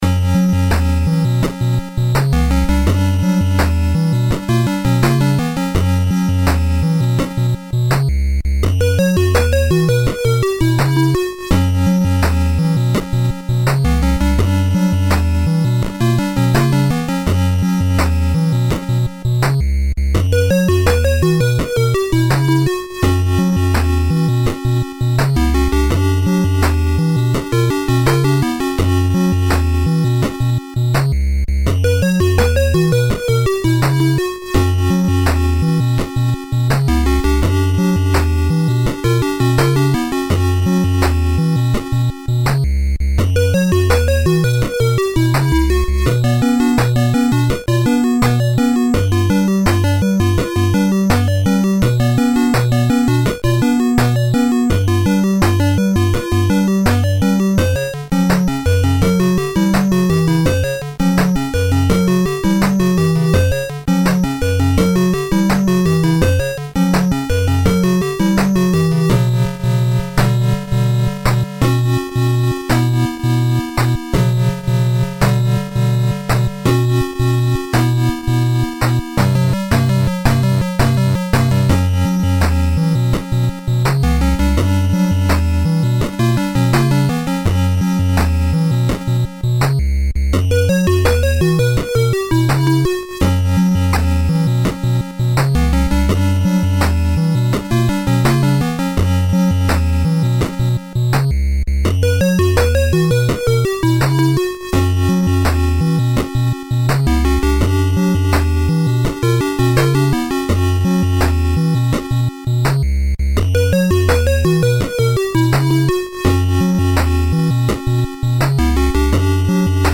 Dendy Game Music